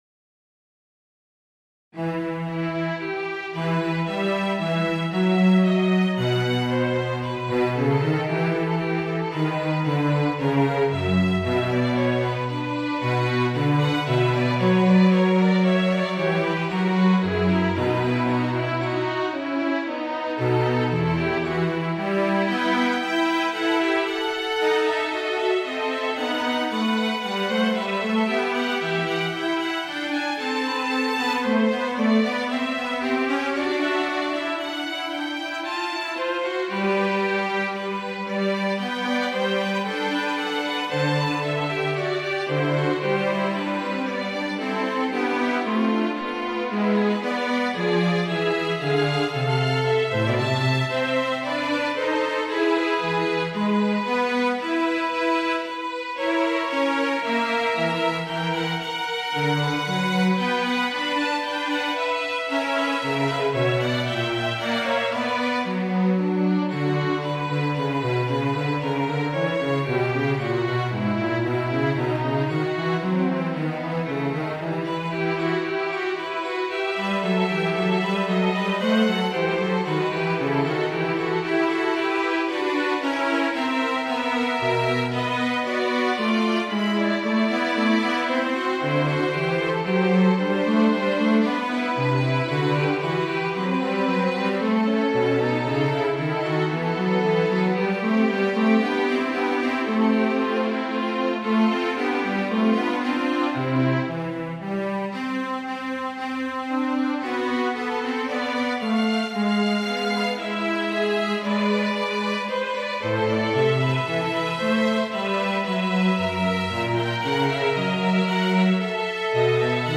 - Moderato en trois parties à trois voix ou instruments